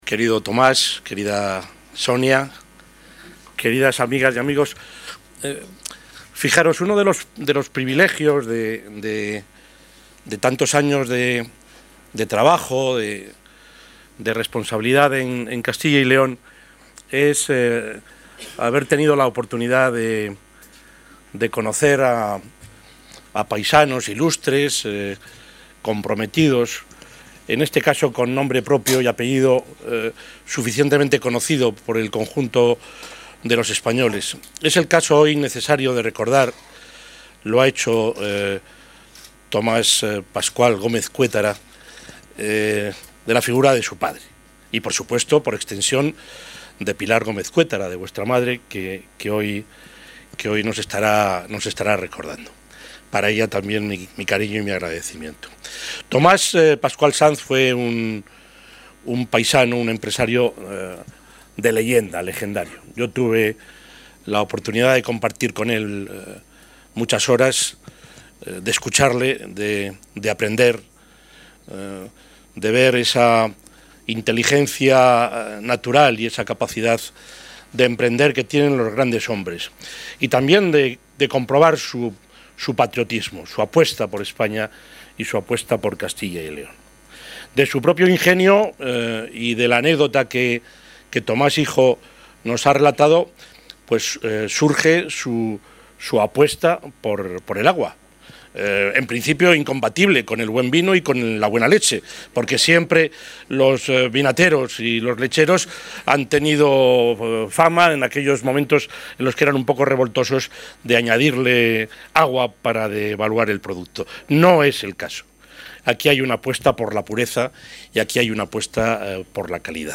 Durante su intervención en el acto de inauguración de la nueva planta de envasado de agua mineral de Bezoya, en la localidad segoviana...
Intervención del presidente de la Junta.